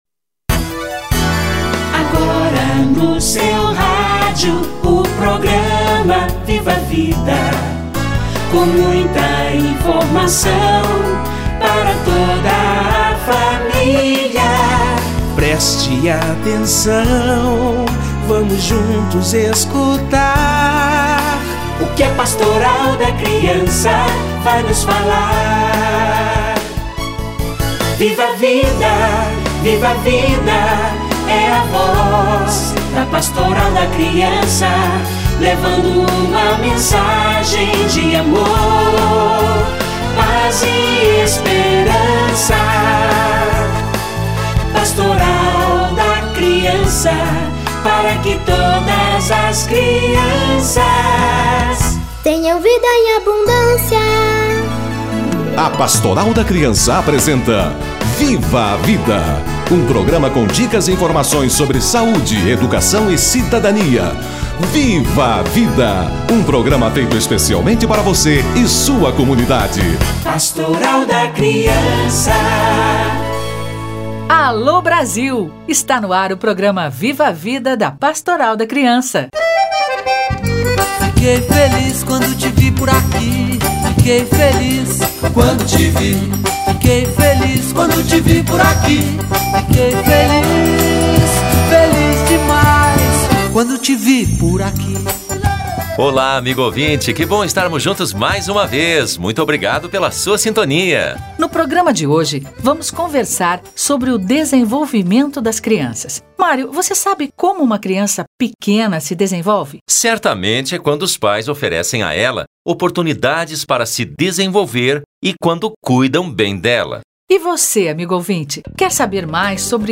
Desenvolvimento infantil - Entrevista